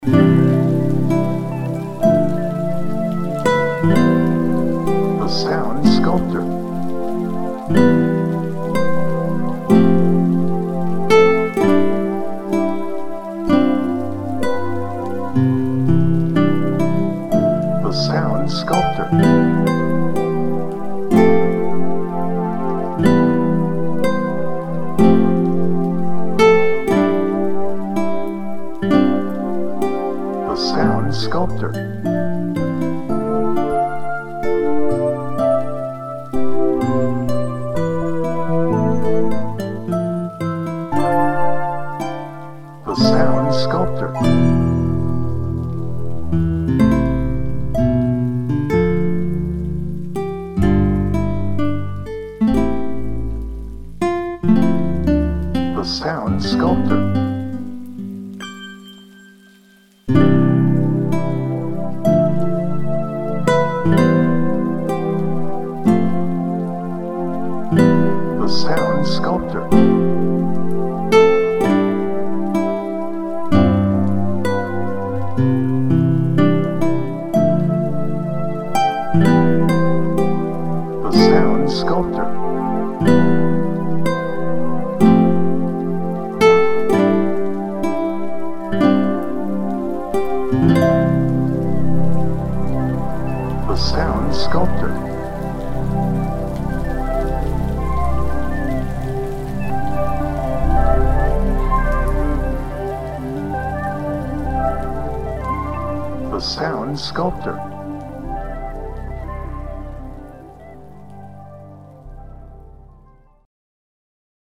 Acoustic
Peaceful
Reflective